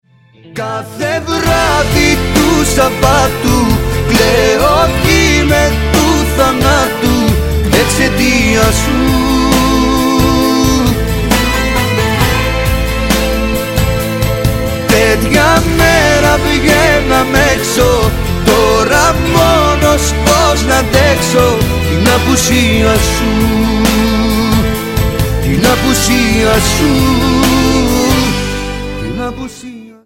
• Качество: 320, Stereo
поп
мужской вокал
восточные
Фрагмент романтичной песни в исполнении греческого певца.